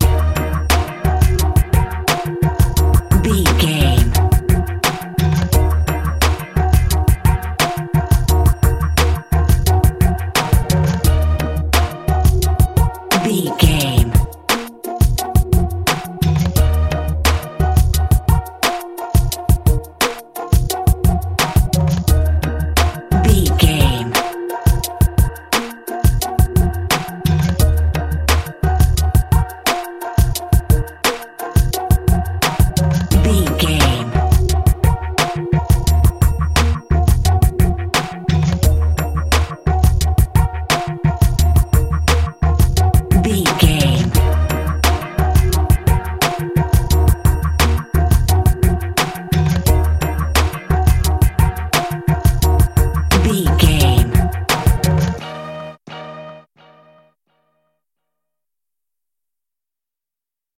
Uplifting
Aeolian/Minor
E♭
drum machine
synthesiser
percussion